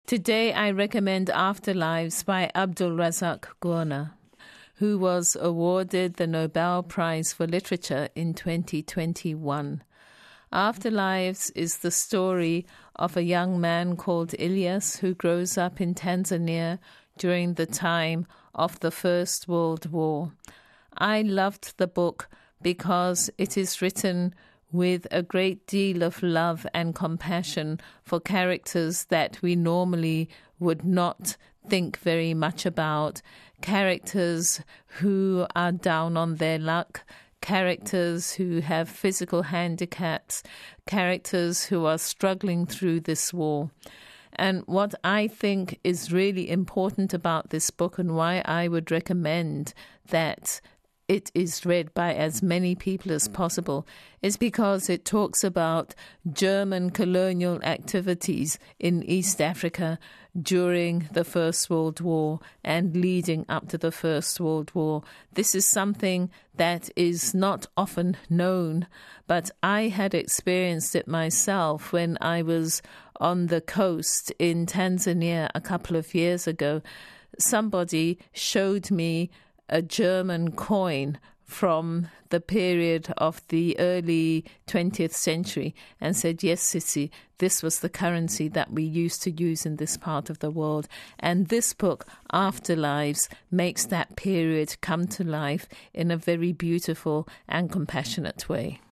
Englische Originalfassung